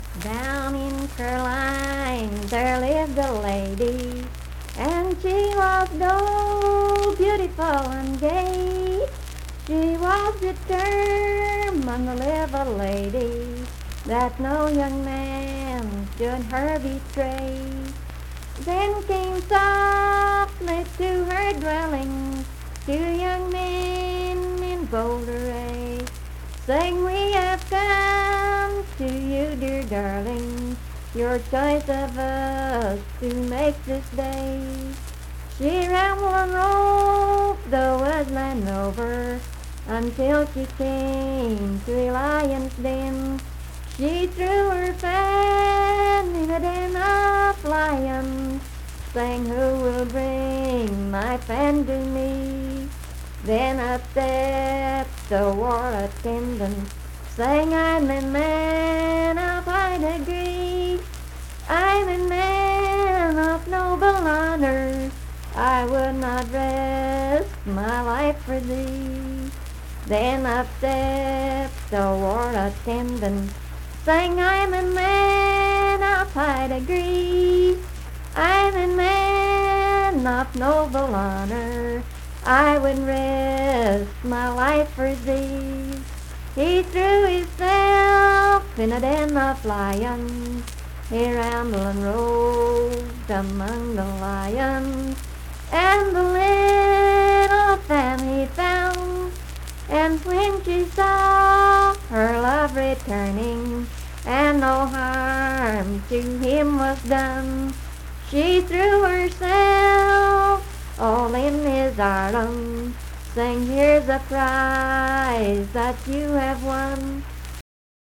Unaccompanied vocal music
Performed in Strange Creek, Braxton, WV.
Voice (sung)